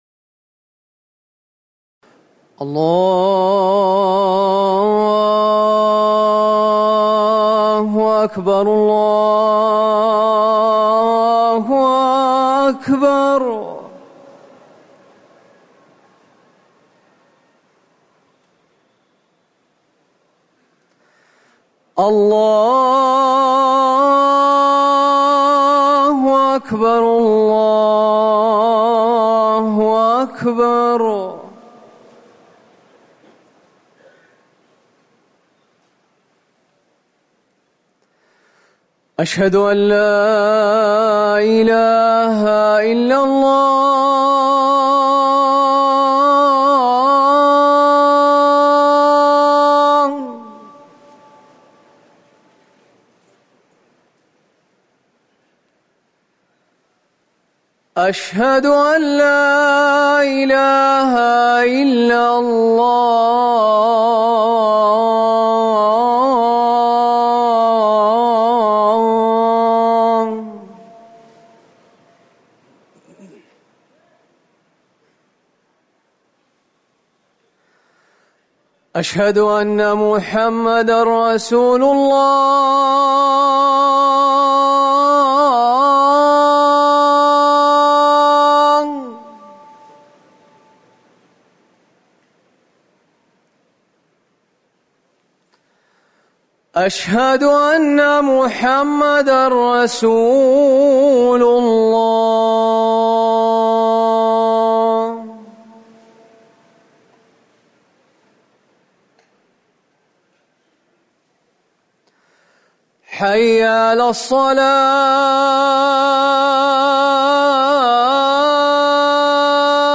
أذان المغرب - الموقع الرسمي لرئاسة الشؤون الدينية بالمسجد النبوي والمسجد الحرام
تاريخ النشر ١٧ محرم ١٤٤١ هـ المكان: المسجد النبوي الشيخ